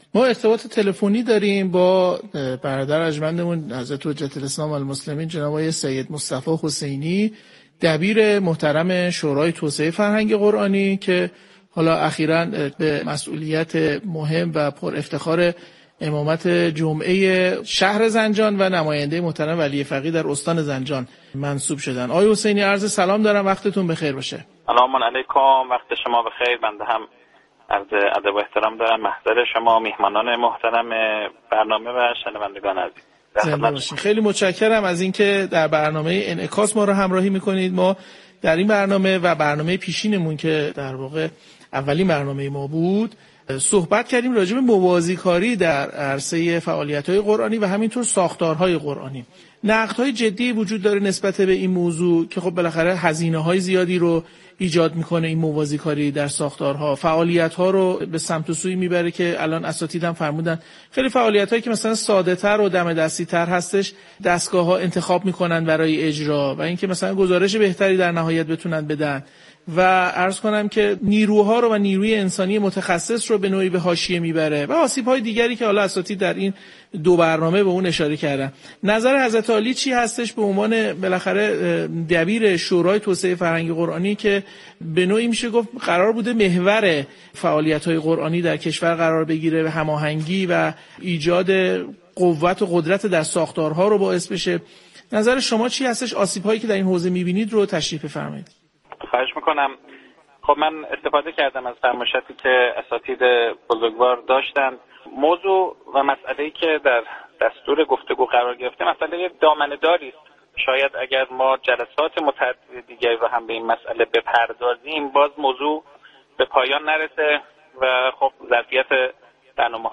دومین قسمت از برنامه جدید «انعکاس» با موضوع موازی‌کاری دستگاه‌ها در امور قرآنی و راهکارهای بهبود آن به روی آنتن شبکه رادیویی قرآن رفت.